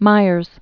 (mīərz)